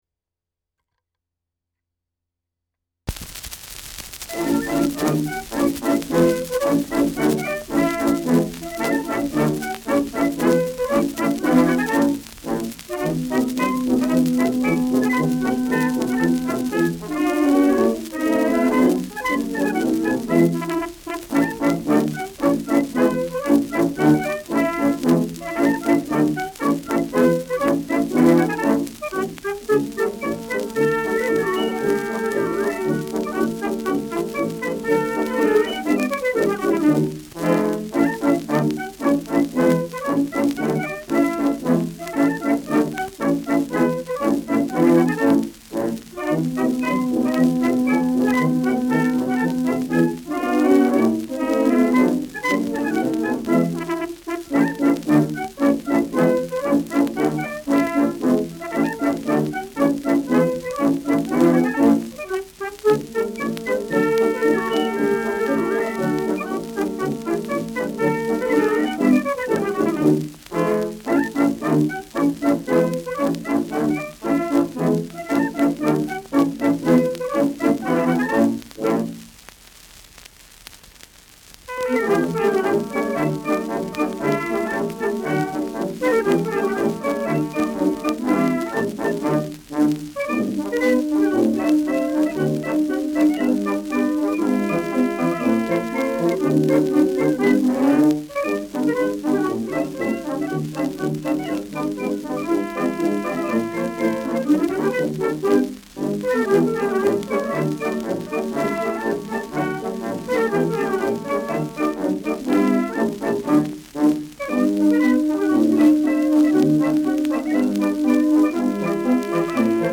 Schellackplatte
leichtes Rauschen : Knistern